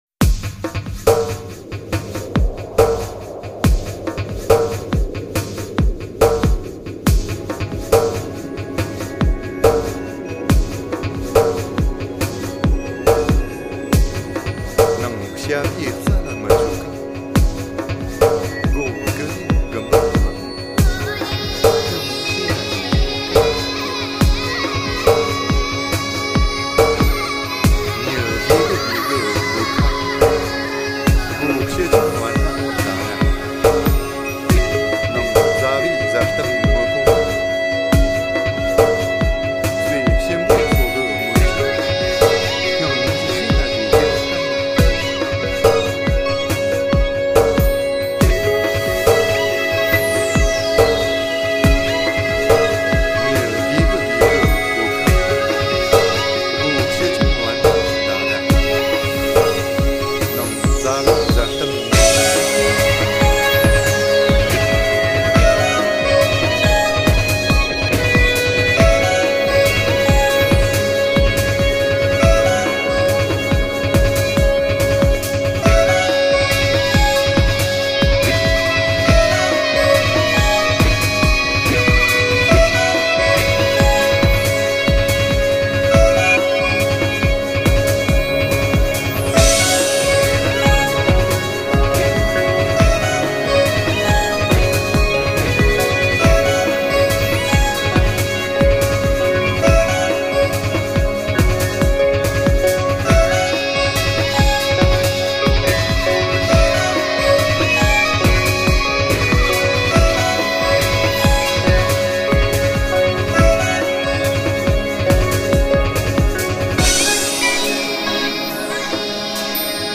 以电子乐的形式来诠释他们心中的青藏高原。